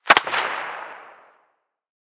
Incendiary_Far_01.ogg